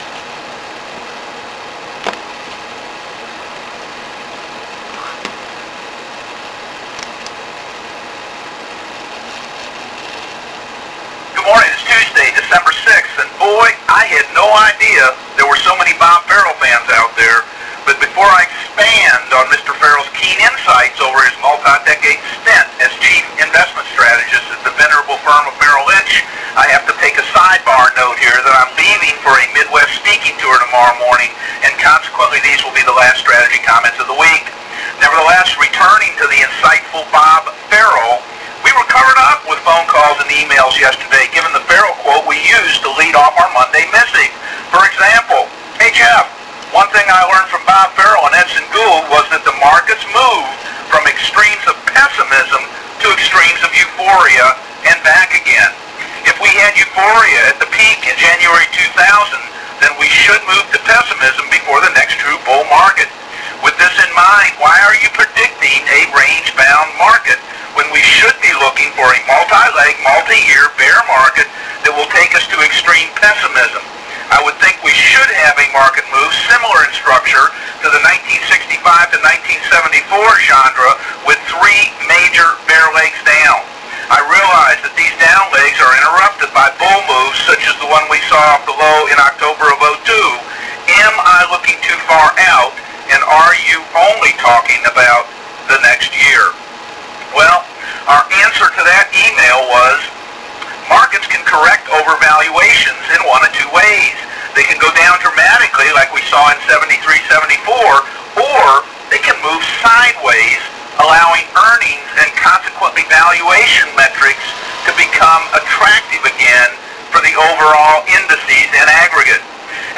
Sorry about the quality of the recording but at the time I didn’t have the nifty Mac “WireTap Studios” capability of recording directly from “line in”.